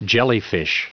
Prononciation du mot jellyfish en anglais (fichier audio)
Prononciation du mot : jellyfish